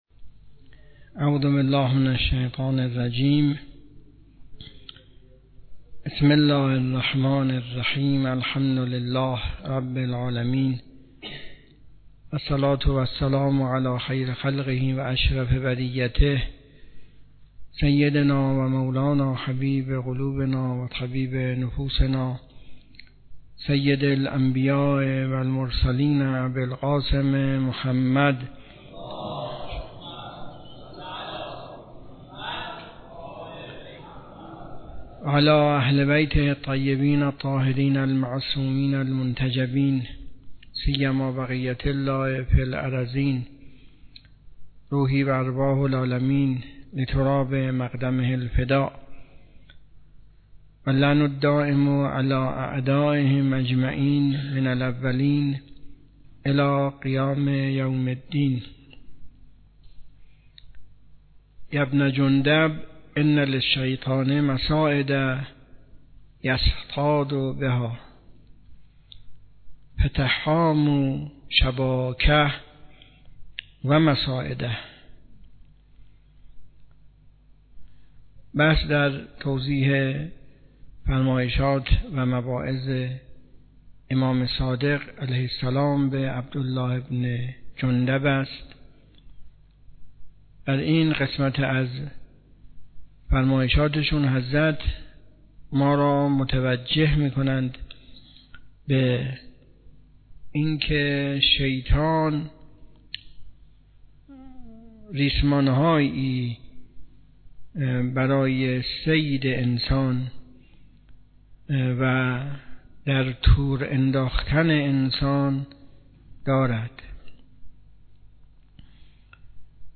حوزه علمیه معیر تهران